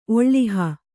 ♪ oḷḷiha